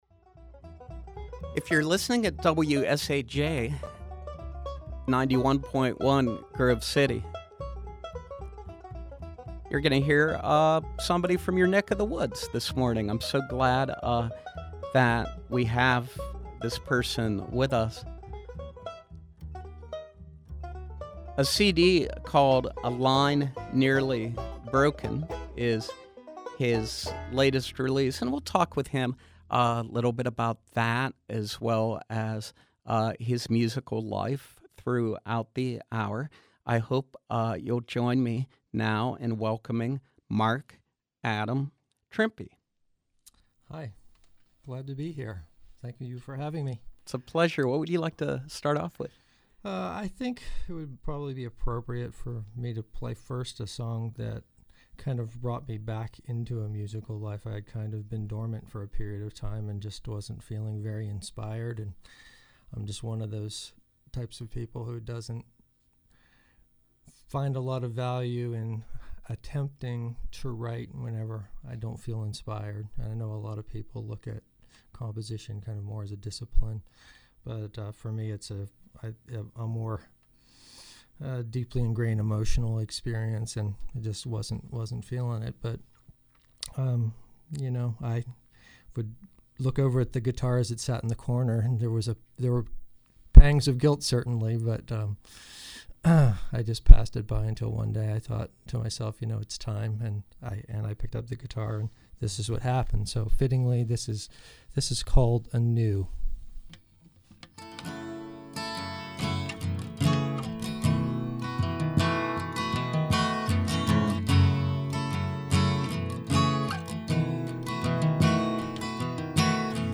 performing acoustic folk-pop